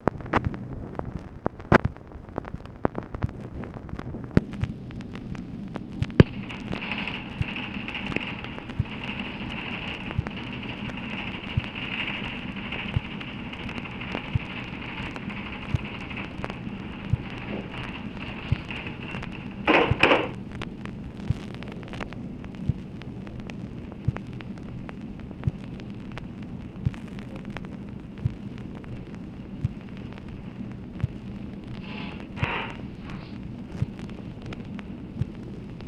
OFFICE NOISE, August 8, 1966
Secret White House Tapes | Lyndon B. Johnson Presidency